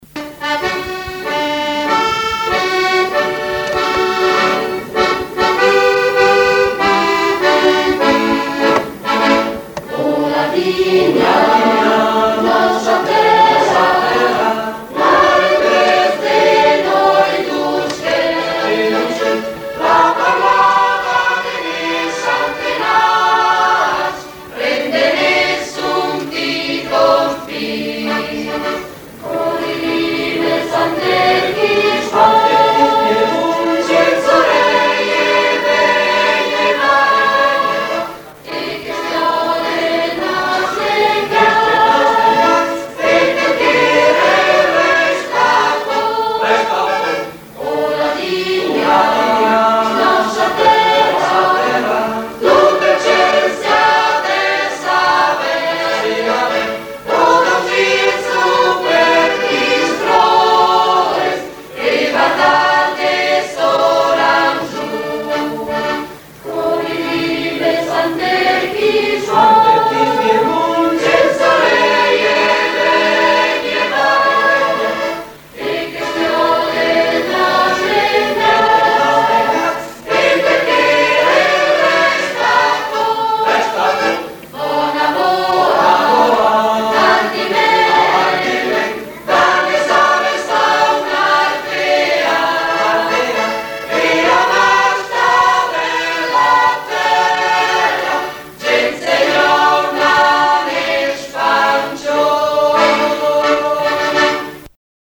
Scouta “Oh, Ladinia” ciantèda dal cor Ousc Ladine da Soraga:
by cor Ousc Ladine da Soraga